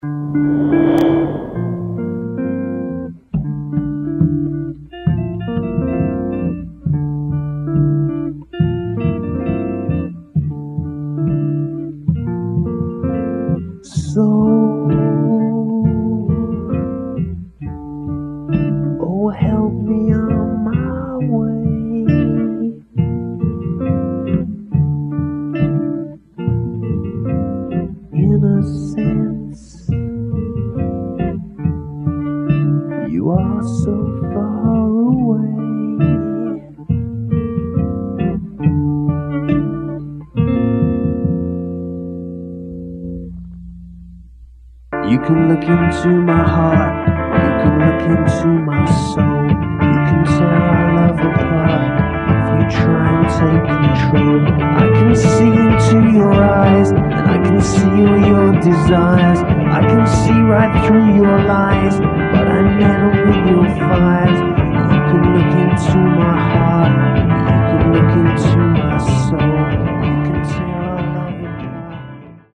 Hard Rock Heavy Rock Indie Rock Vampire Goth Rock